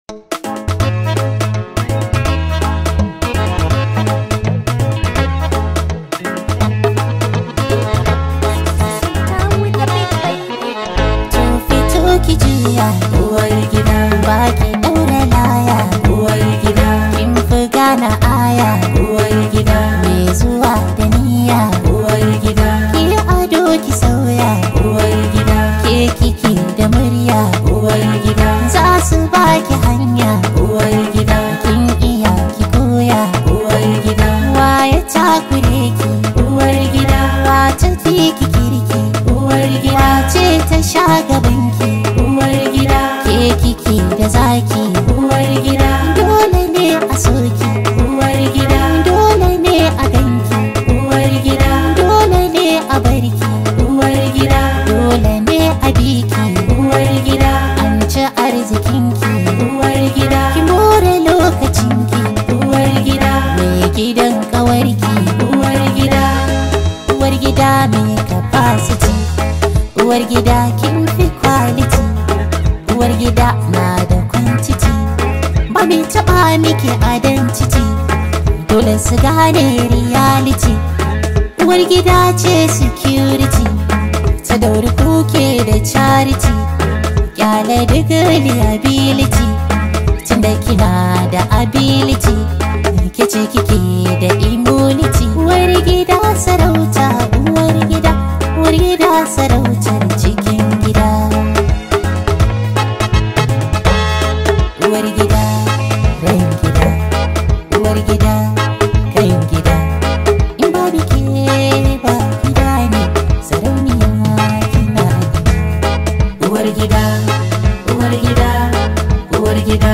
Hausa Music
high vibe hausa song